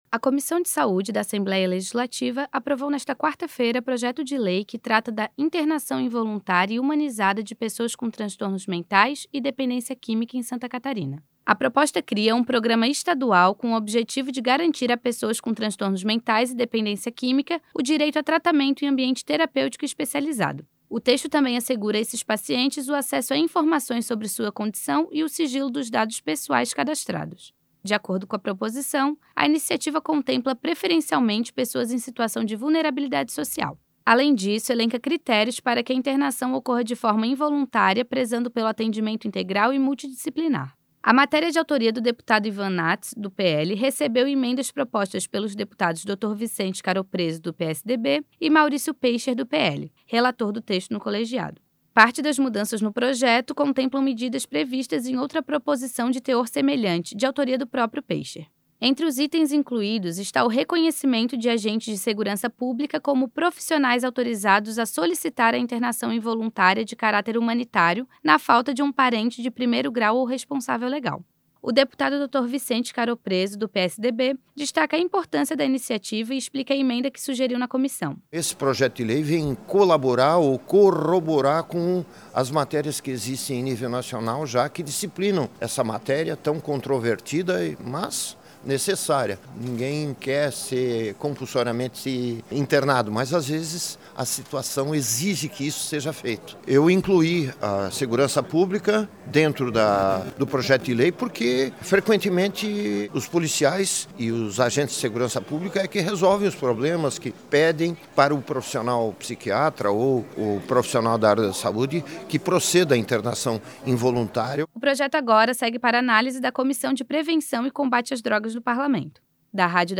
O deputado Dr. Vicente Caropreso (PSDB) destacou a importância da iniciativa e explica a emenda que sugeriu na Comissão.
Entrevista com:
- deputado Dr. Vicente Caropreso, vice-presidente da Comissão de Saúde.